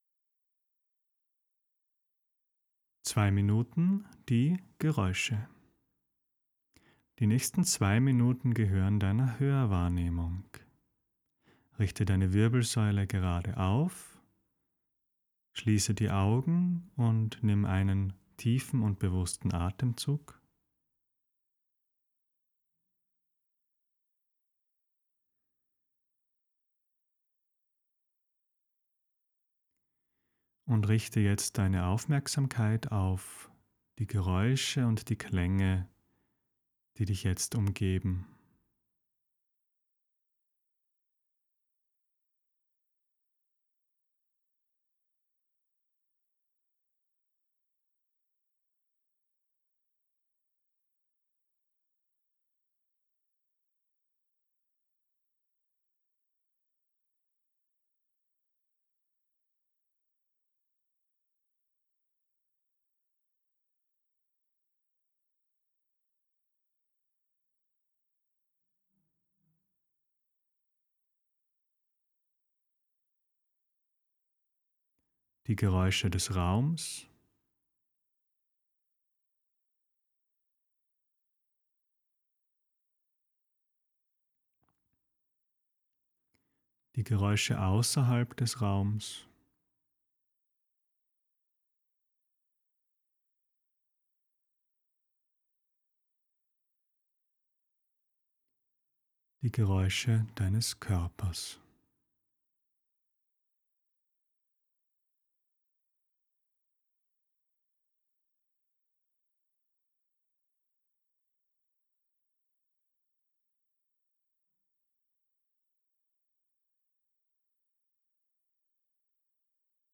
In dieser kurzen Übung lenkst du deine Aufmerksamkeit auf die Geräusche, die dich in diesem Moment umgeben. Audioanleitung und Erklärung zum Lesen.